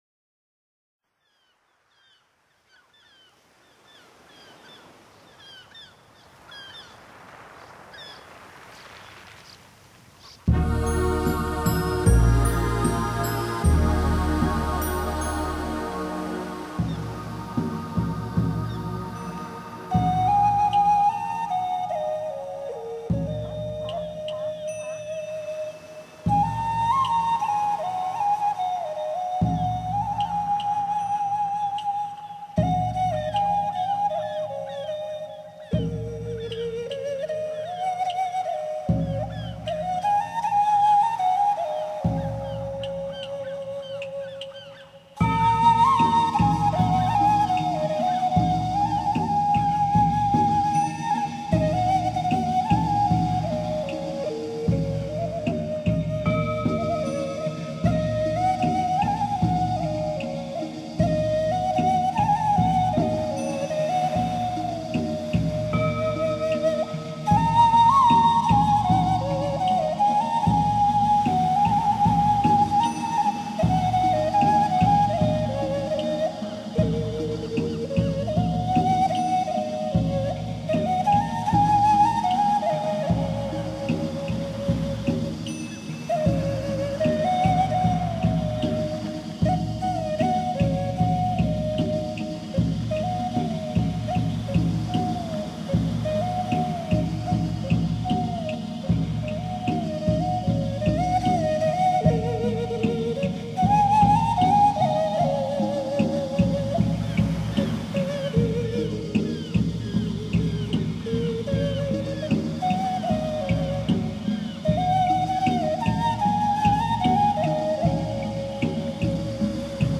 集合中西乐手 以现代电子合成乐融汇东方古老禅思哲意